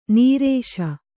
♪ nīrēśa